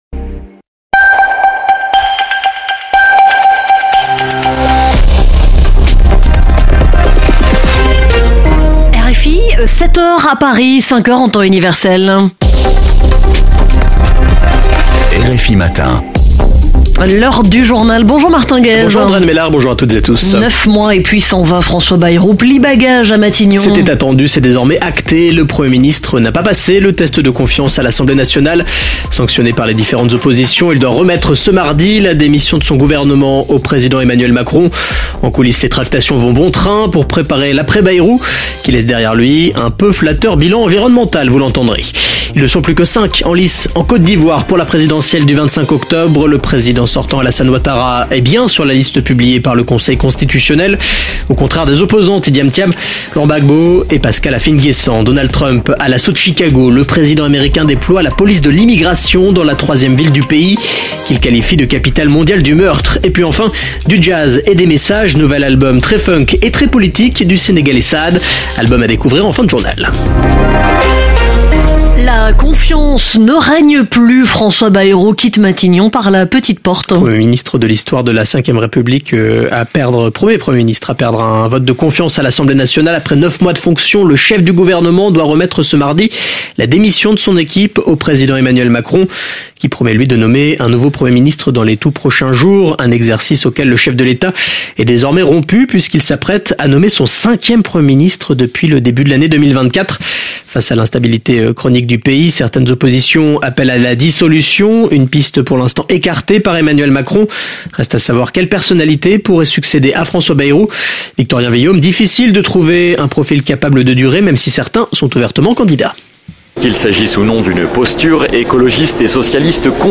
Flash infos 10/04/2026